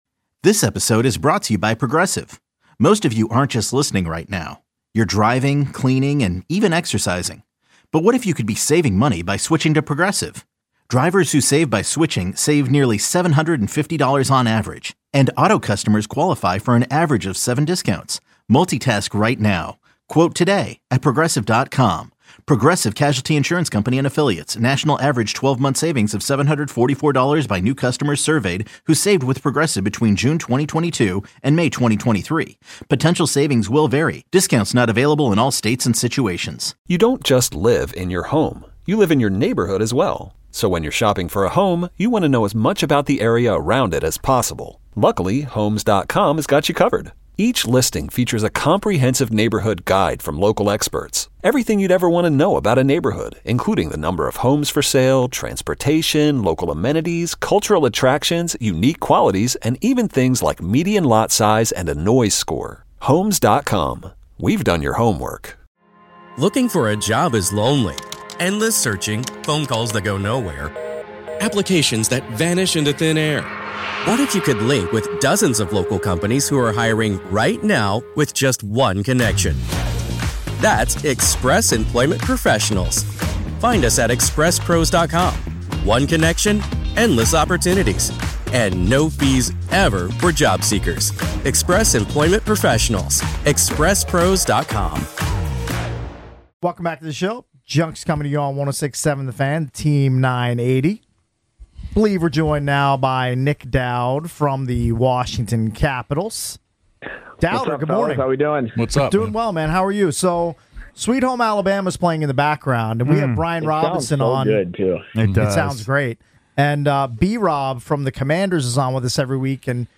Nic Dowd joins The Sports Junkies to break down the Capitals recent games.